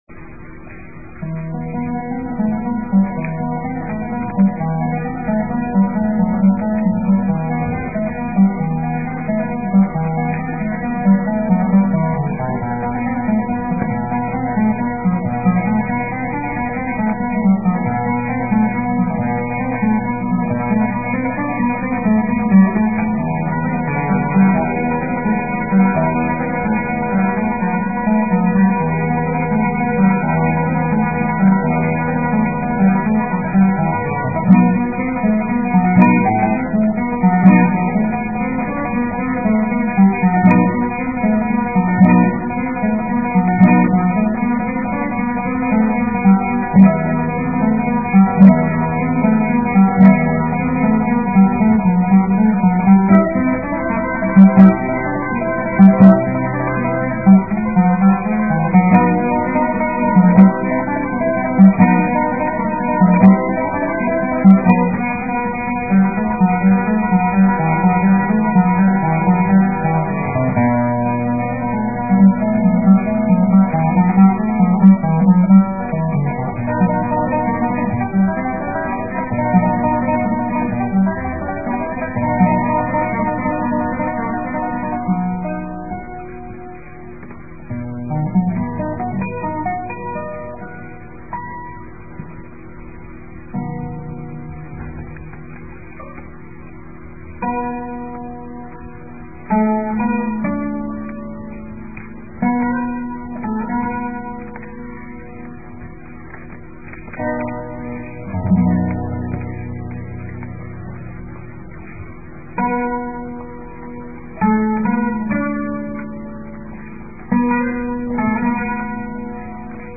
Classic Guitar Partiturs